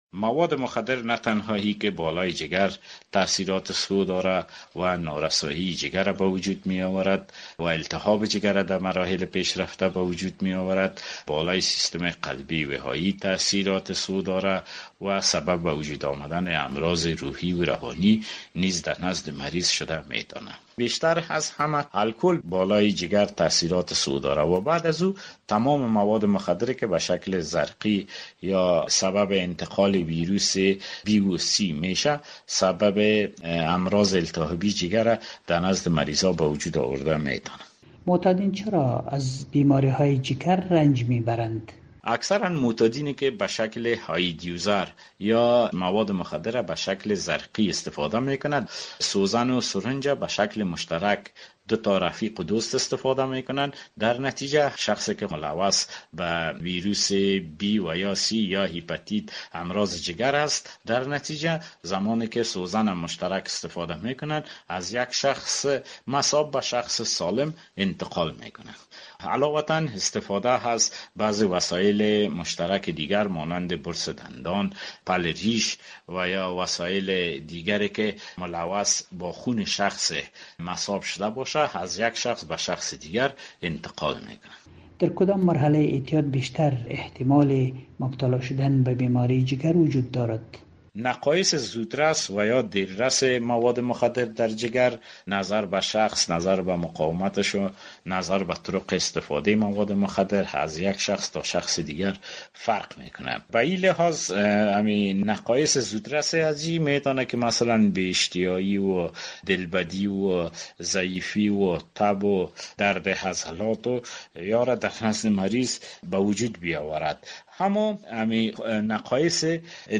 همکار ما در مصاحبه با